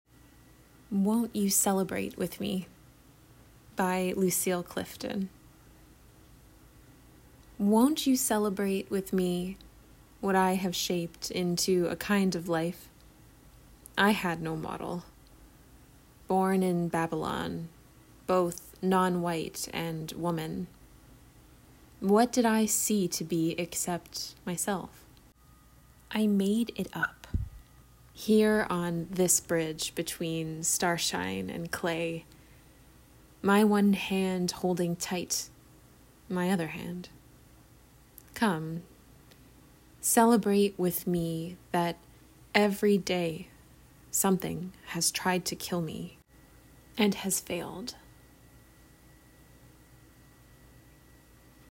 Enjoy spoken word recordings on poems and writings within the theme of resiliency, perseverance, and grit.